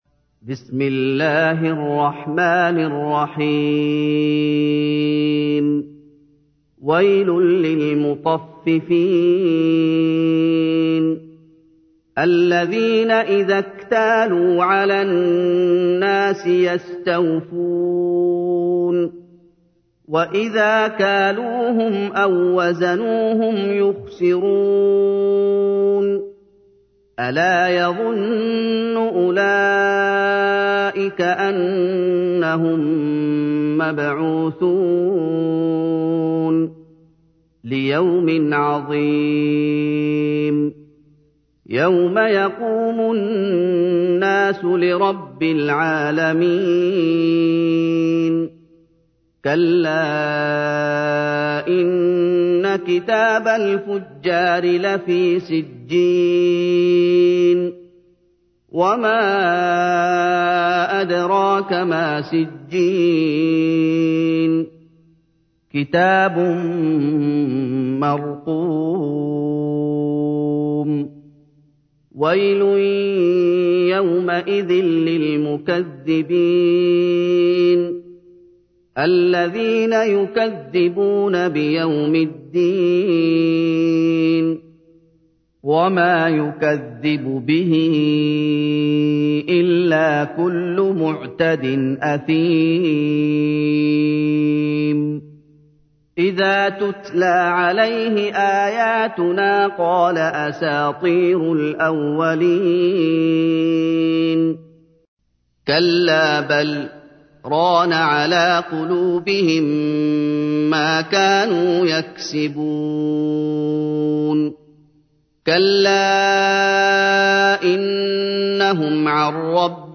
تحميل : 83. سورة المطففين / القارئ محمد أيوب / القرآن الكريم / موقع يا حسين